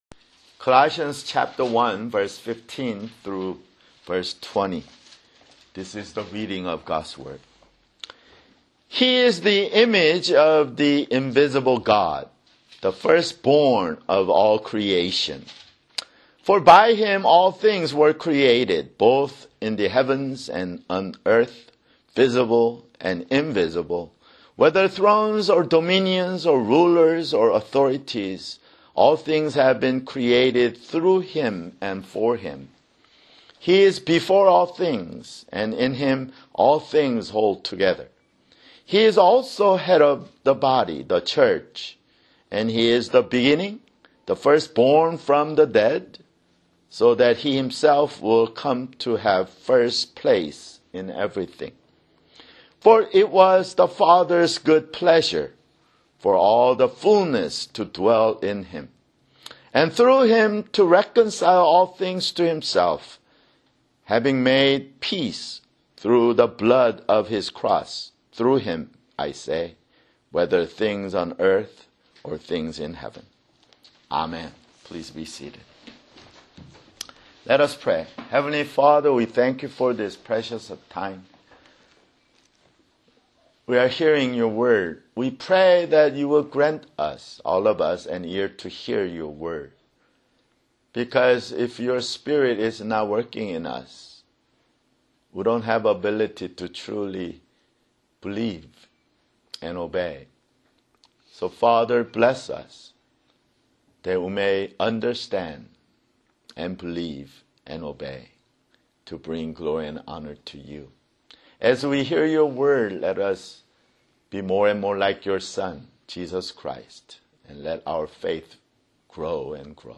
[Sermon] Colossians (25)